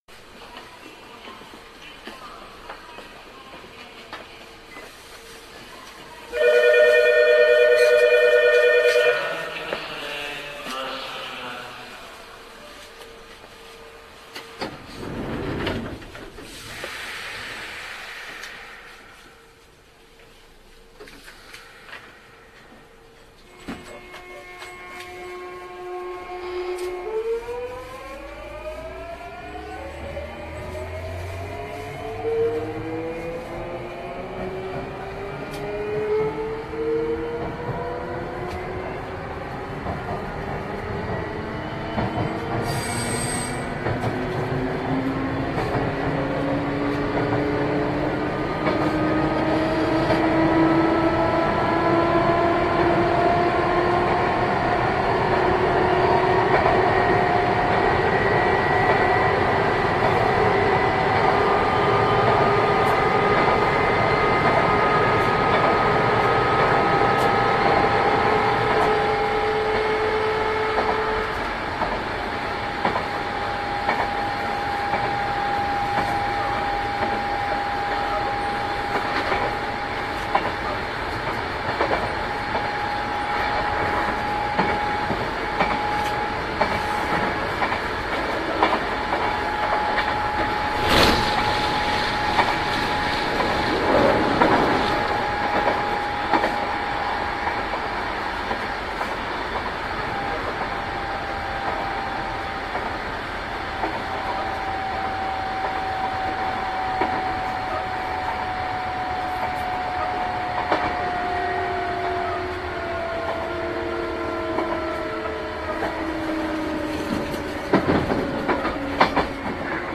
同じ編成（ただし車両は別）ですが、こちらは空転していません。基本的にトンネル内は好きじゃないんですが、相鉄の場合はむしろ音の迫力が増してきますね。
それから、ブレーキの操作時に若干カチッと音が聞こえると思いますが、車端にあるボックスから聞こえています。
相鉄本線　大和〜瀬谷（5154）